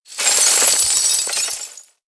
CHQ_VP_raining_gears.ogg